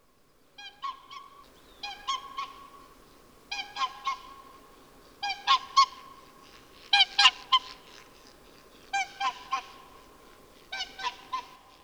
laulujoutsen.aiff